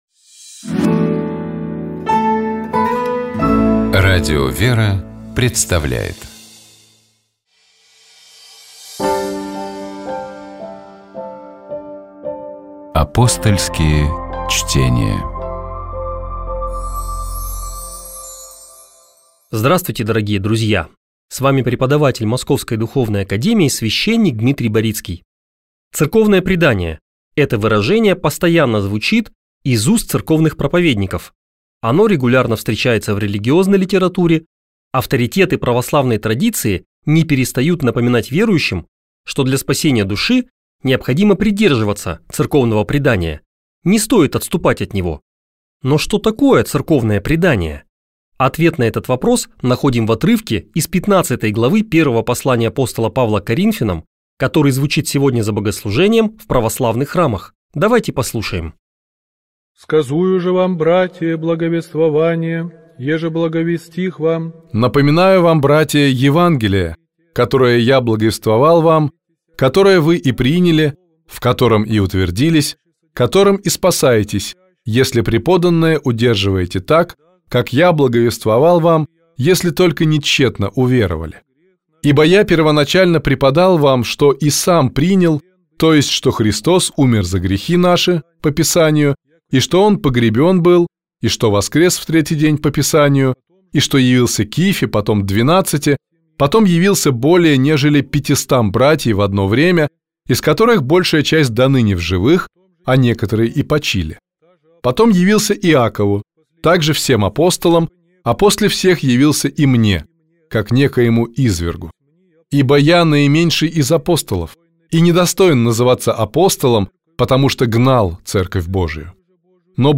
Беседа из цикла про образование, который Радио ВЕРА организует совместно с образовательным проектом «Клевер Лаборатория», которая объединяет учителей, руководителей школ и детских садов, родителей и всех тех, кто работает с детьми и занимается их духовно-нравственным развитием.